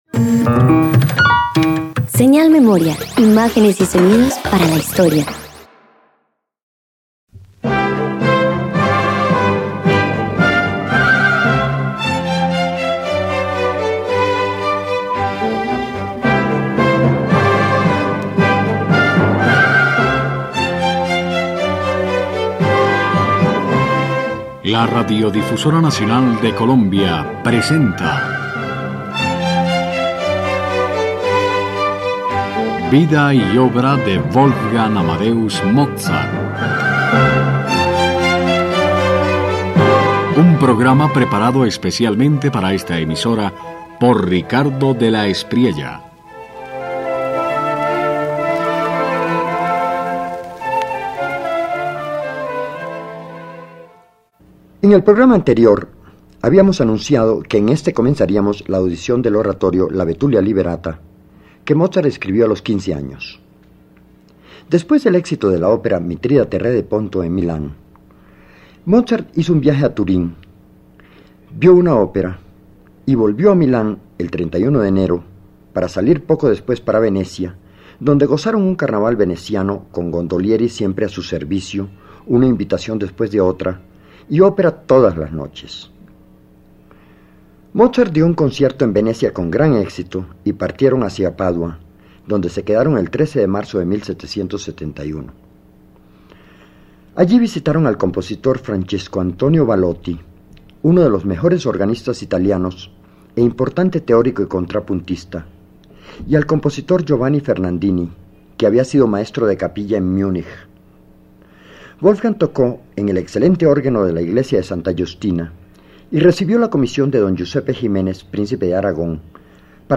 045 Oratorio La Betulia Liberata Parte I_1.mp3